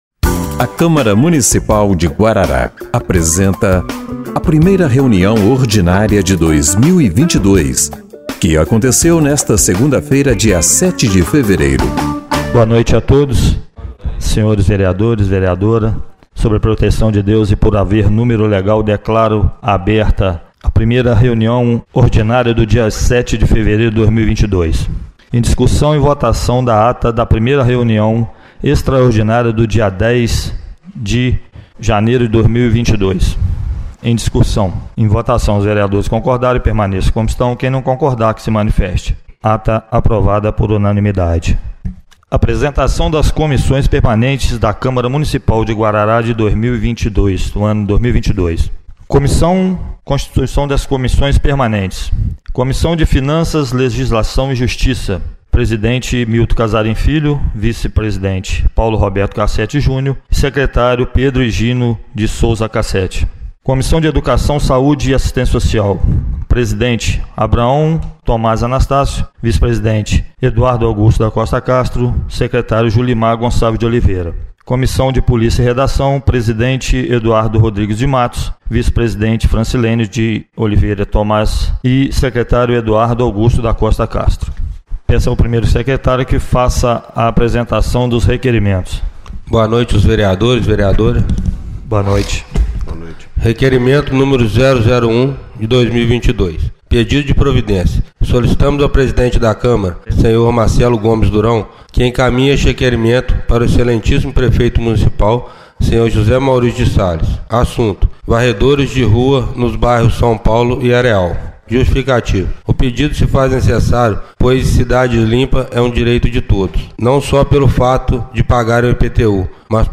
1ª Reunião Ordinária de 07/02/2022